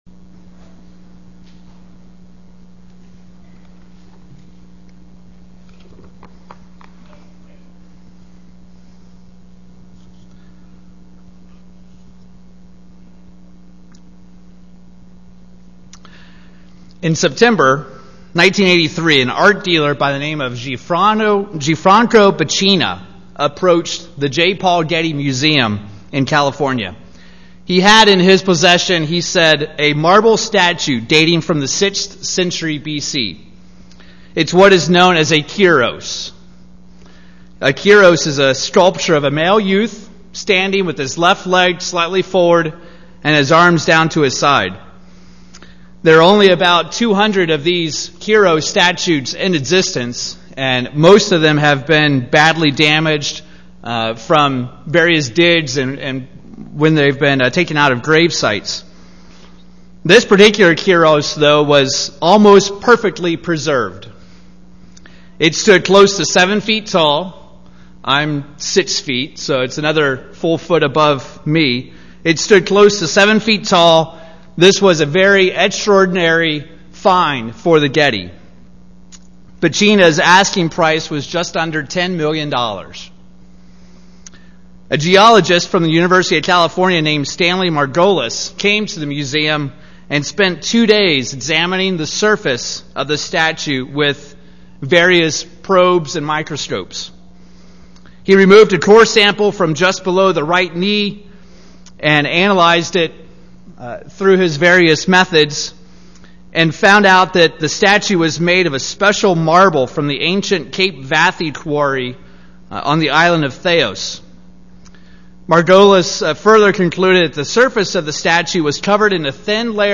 These are the notes taken live during services as captioning for the deaf and hard of hearing.